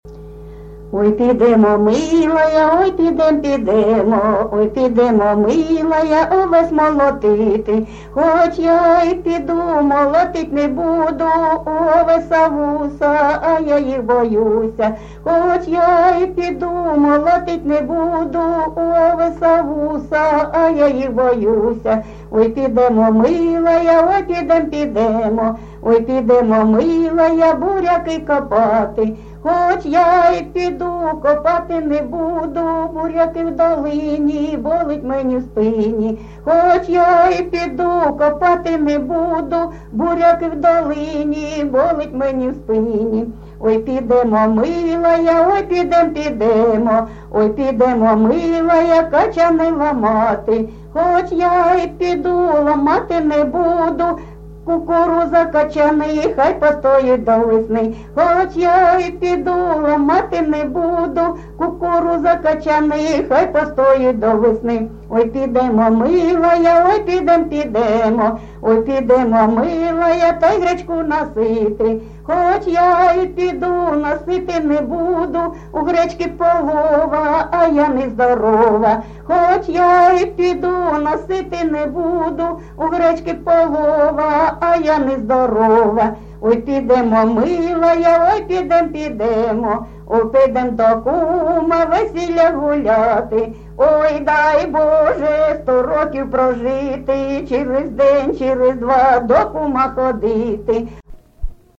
ЖанрЖартівливі
Місце записум. Часів Яр, Артемівський (Бахмутський) район, Донецька обл., Україна, Слобожанщина